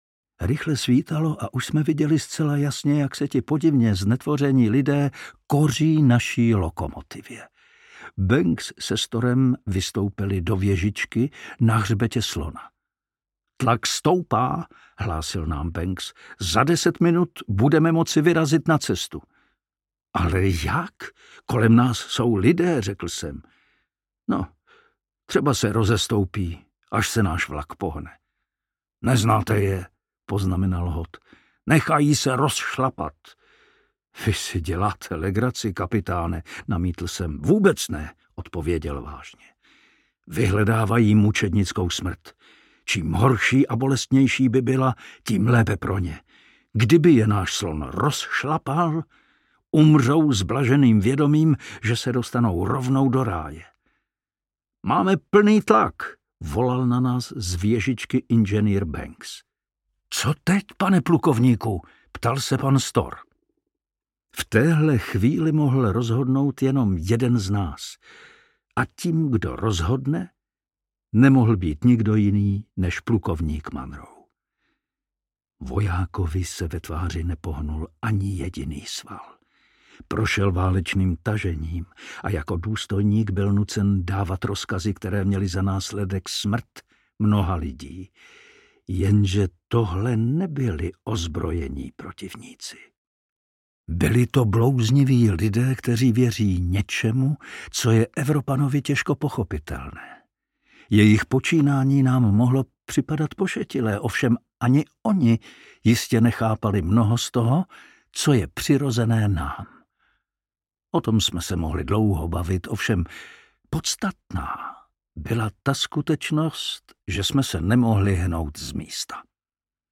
Zemí šelem audiokniha
Ukázka z knihy
zemi-selem-audiokniha